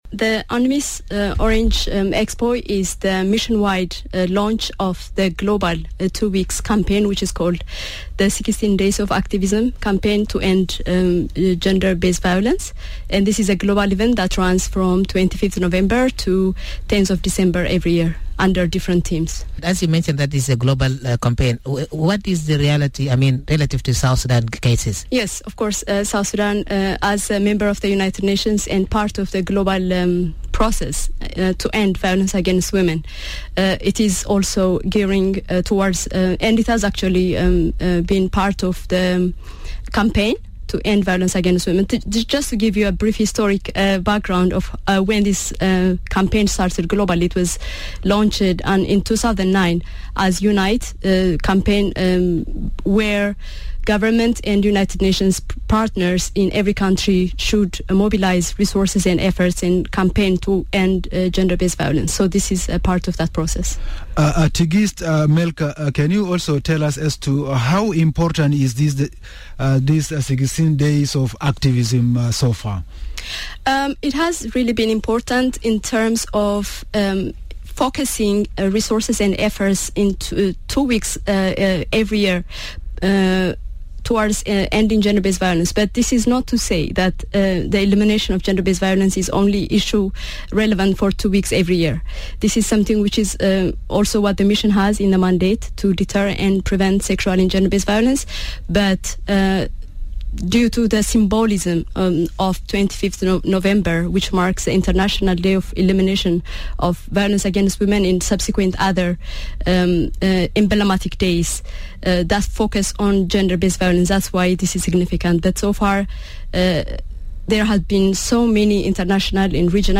Radio Miraya
Miraya Breakfast show hosts